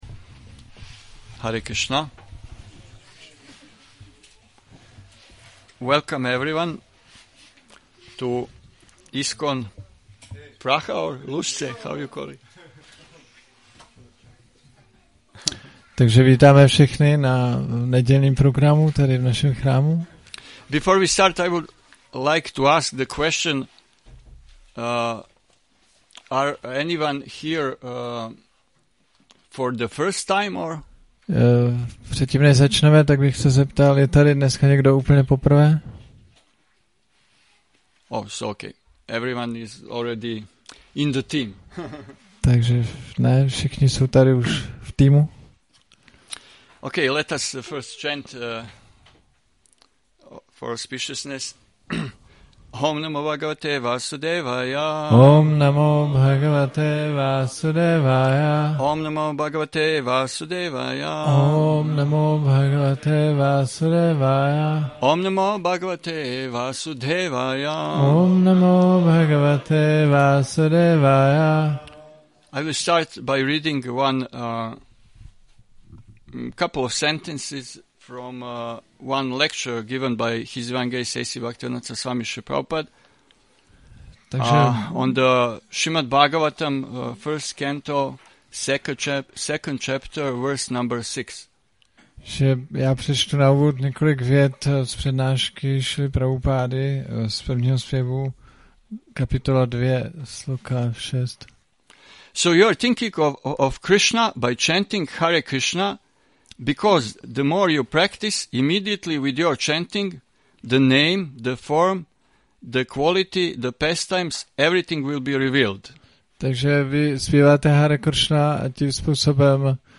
Přednáška Nedělní program